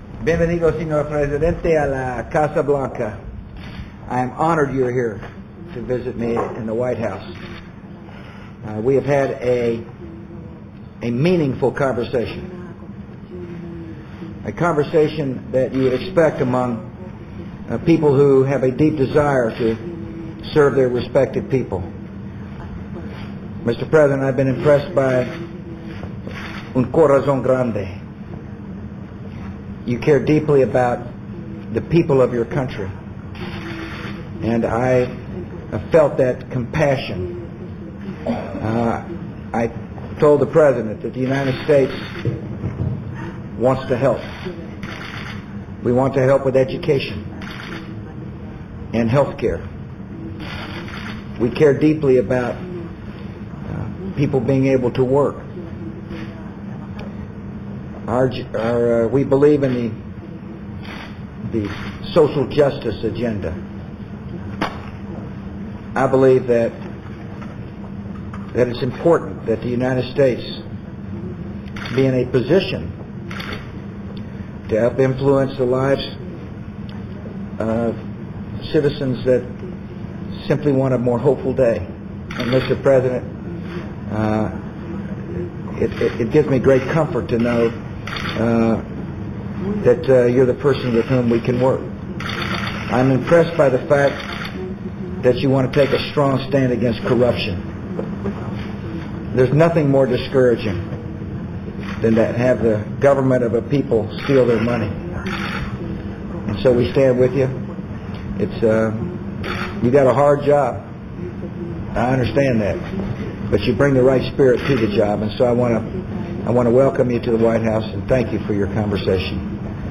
U.S. President George W. Bush speaks with President Lugo of Paraguay in the Oval Office